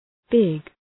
Προφορά
{bıg}